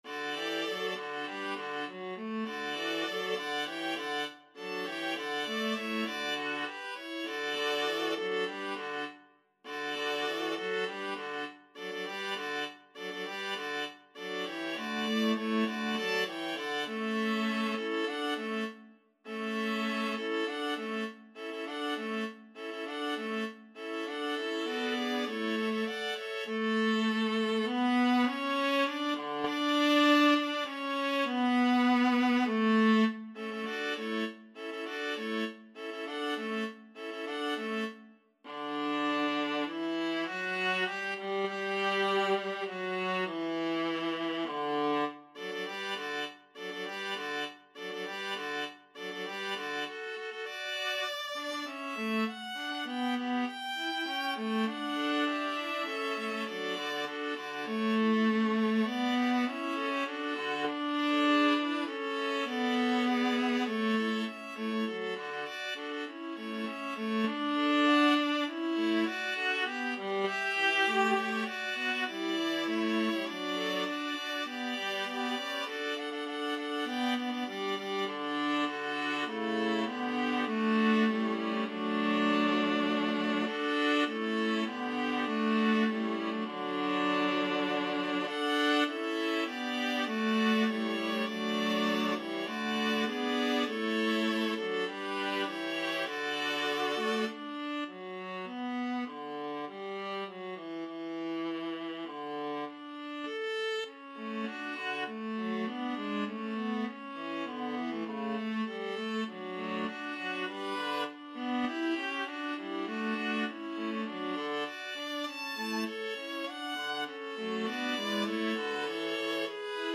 Free Sheet music for Viola Quartet
Viola 1Viola 2Viola 3Viola 4
D major (Sounding Pitch) (View more D major Music for Viola Quartet )
4/4 (View more 4/4 Music)
Allegro (View more music marked Allegro)
Viola Quartet  (View more Intermediate Viola Quartet Music)
Classical (View more Classical Viola Quartet Music)
messiah_hallelujah_4VLA.mp3